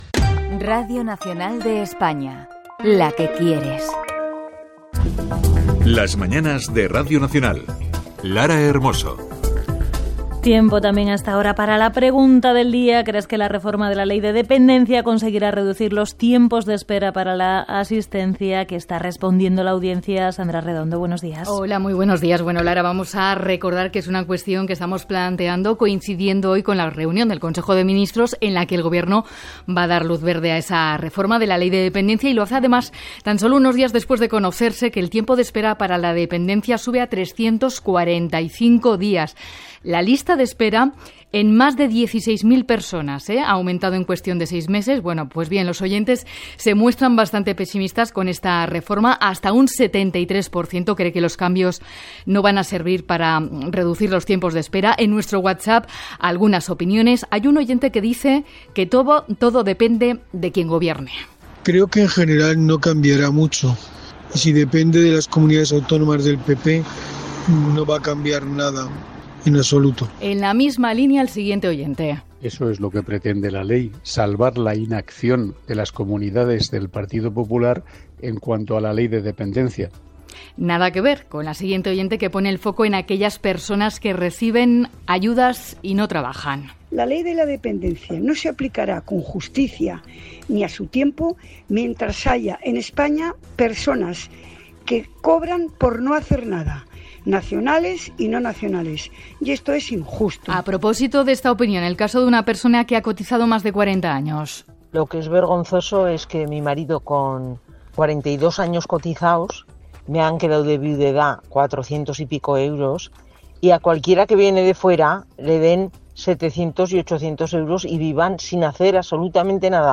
Indicatiu del programa, respostes de l'audiència sobre la pregunta relacionada amb la Llei de la Dependència, indicatiu, els disturbis a Torre Pacheco (Múrcia) amb declaracions del ministre Oscar López i la presidenta de la Comunitat de Madrid Isabel Díaz Ayuso
Info-entreteniment
FM